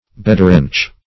Search Result for " bedrench" : The Collaborative International Dictionary of English v.0.48: Bedrench \Be*drench"\, v. t. [imp.